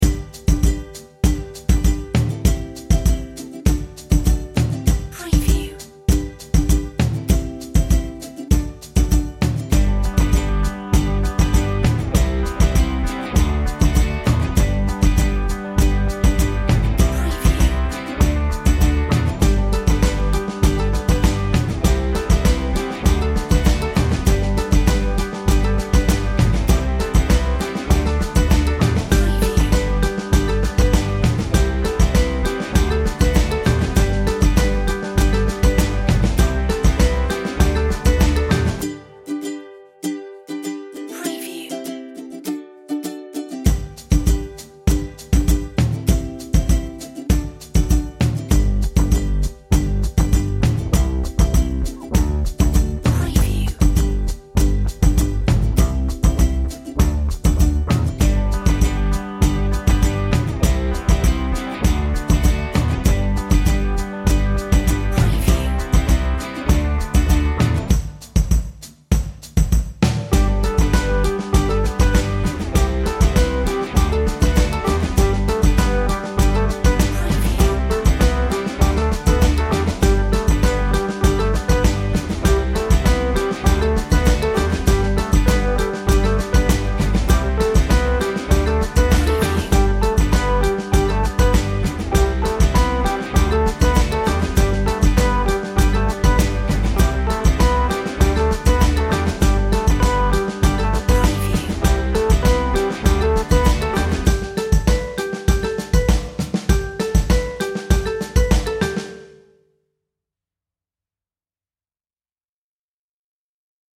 Happy acoustic ukulele